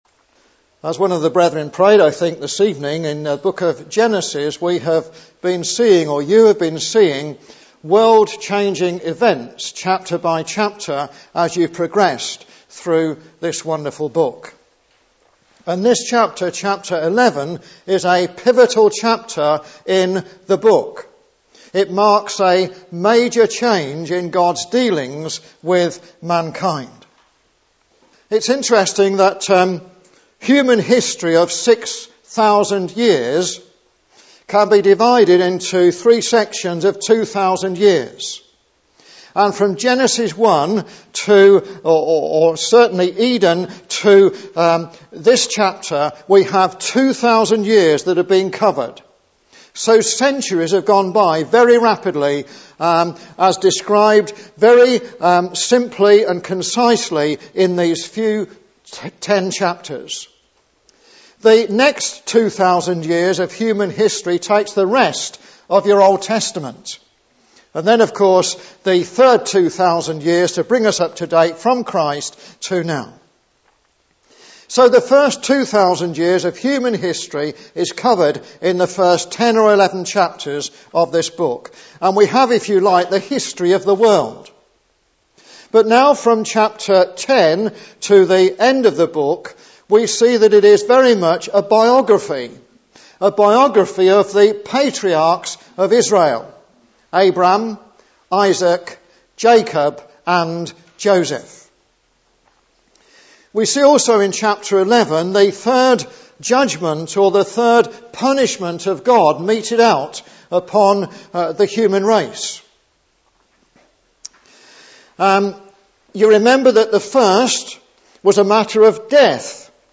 Exposition of Genesis 11 (47 mins)